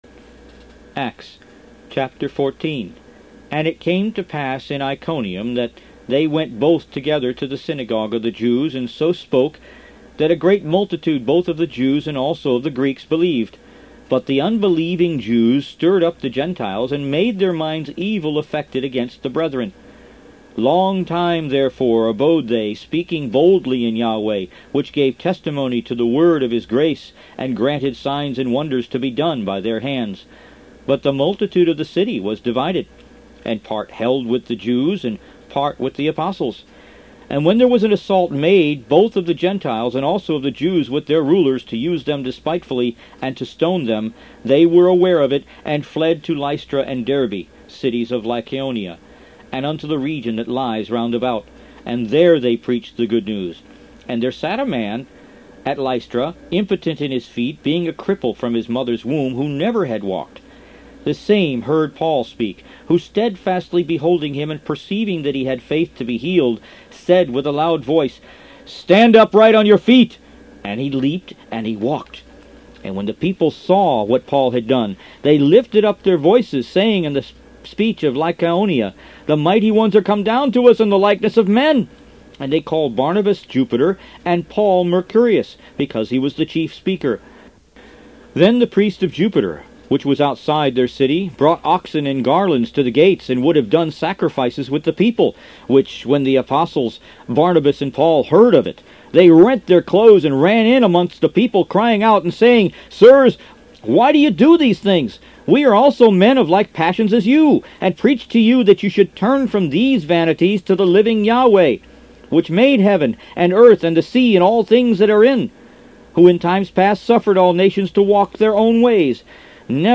Root > BOOKS > Biblical (Books) > Audio Bibles > Messianic Bible - Audiobook > 05 The Book Of Acts